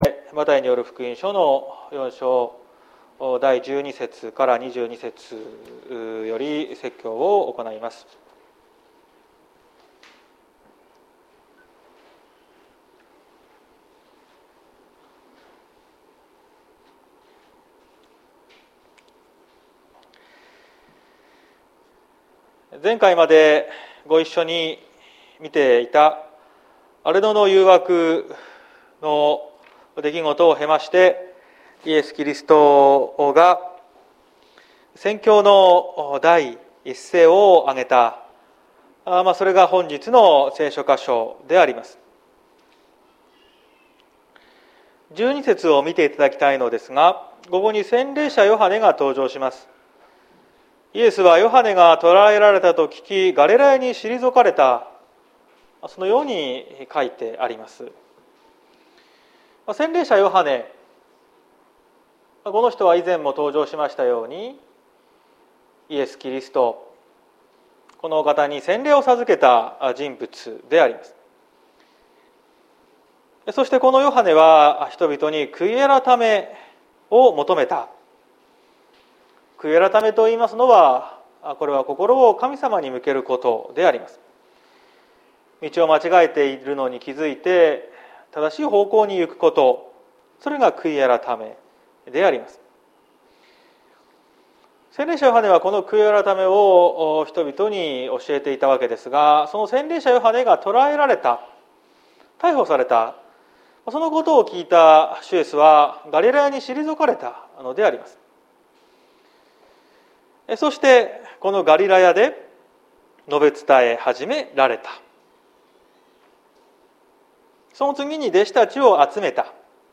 2022年05月08日朝の礼拝「天の国が今、ここに」綱島教会
説教アーカイブ。
毎週日曜日の10時30分から神様に祈りと感謝をささげる礼拝を開いています。